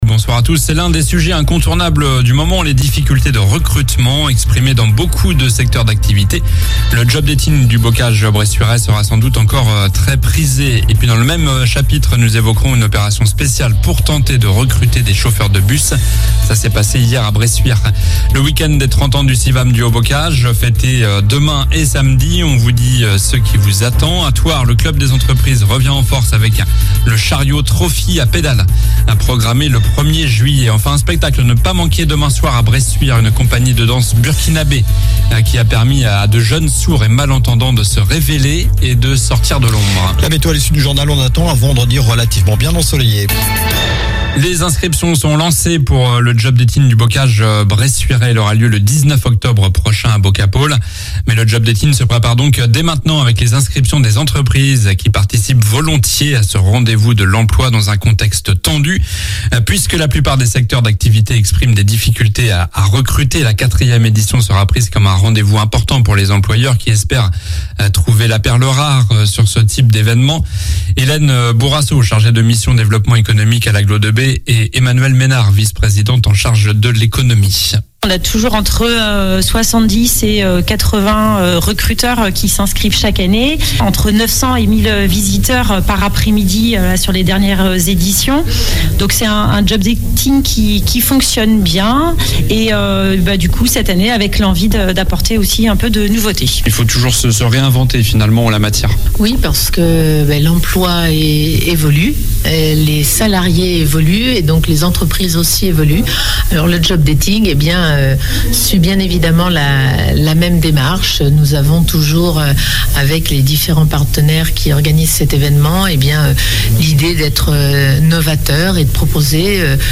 Difficultés de recrutement : Le job dating du bocage bressuirais sera sans doute encore très prisé. - Reportage à Bressuire sur une opération spéciale pour tenter de recruter des chauffeurs de bus. - Le week-end des 30 ans du CIVAM du Haut Bocage fêté demain et samedi.